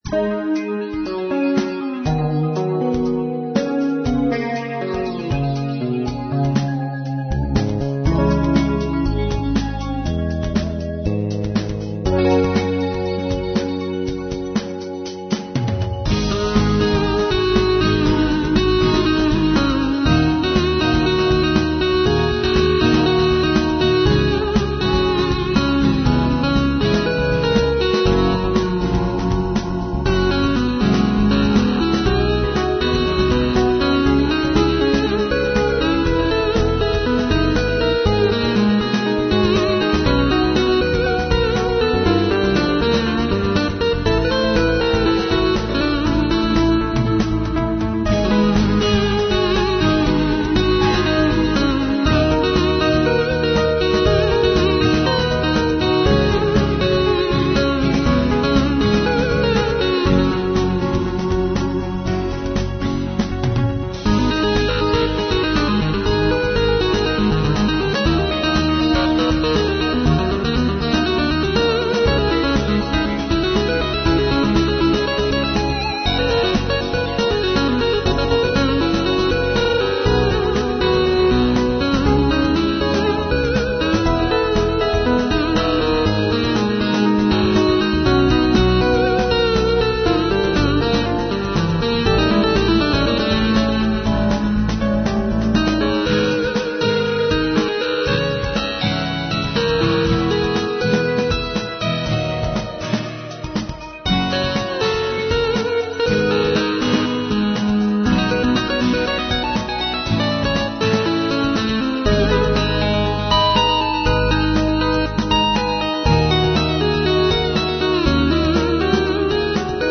Dramatic Instrumental Rock with feel of Tension